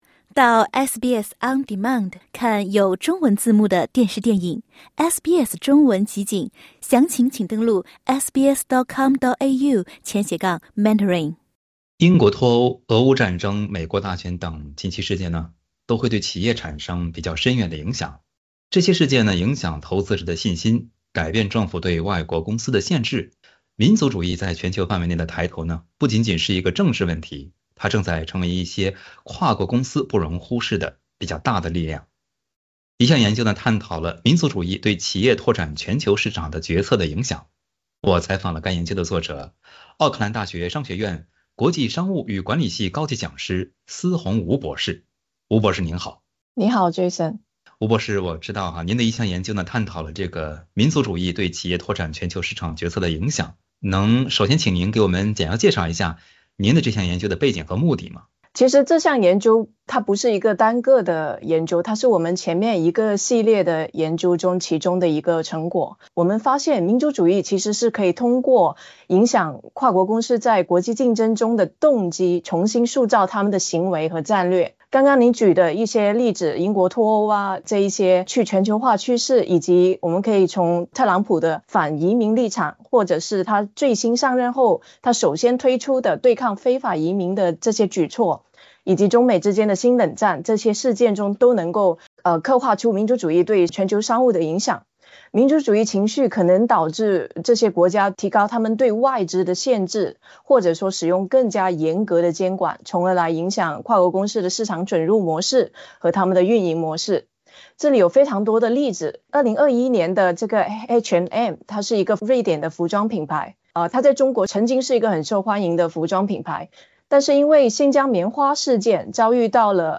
技术民族主义成为拓展海外市场的企业不容忽视的力量 Play 08:28 Flags of the world Source: Pixabay SBS 普通话电台 View Podcast Series 下载SBS Audio应用程序 其他收听方式 Apple Podcasts  YouTube  Spotify  Download (7.76MB)  什么是技术民族主义？